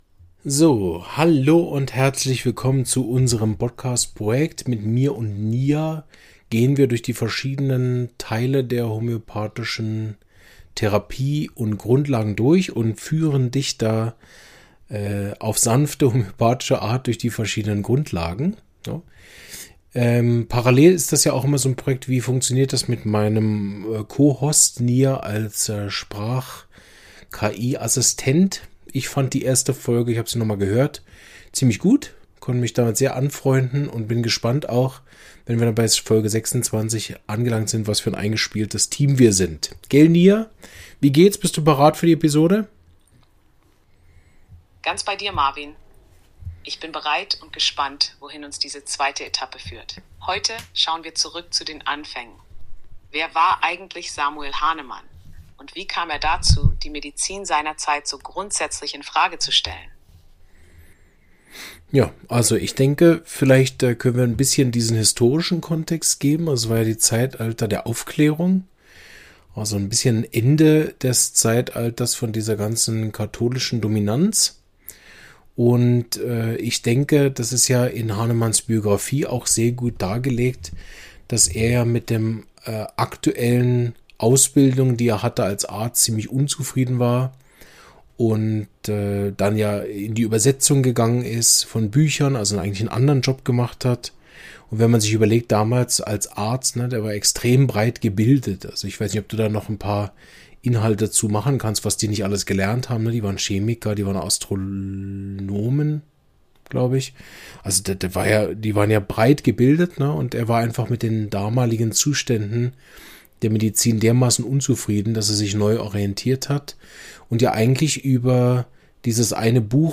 Wer hat die Homöopathie entdeckt – und wie? ~ Homöopathie erklärt – im Dialog mit einem KI-System Podcast